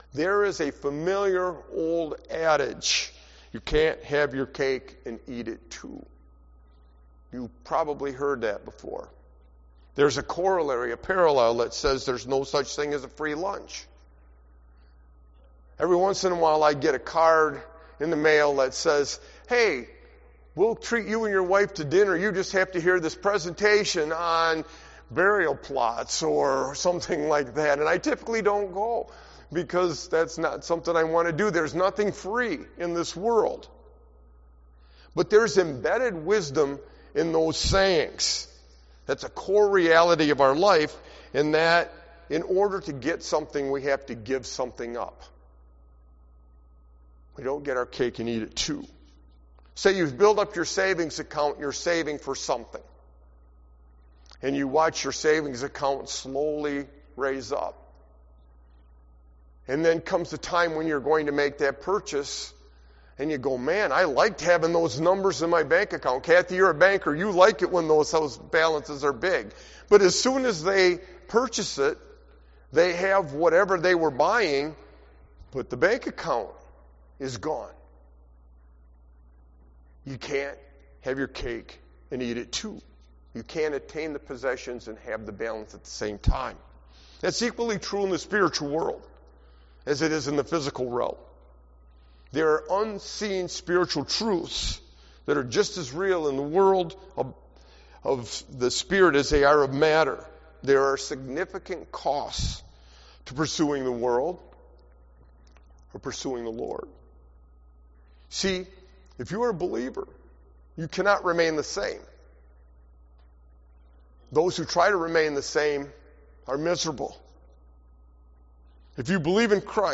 Sermon-Why-a-Sovereign-God-is-crucial-II-12322.mp3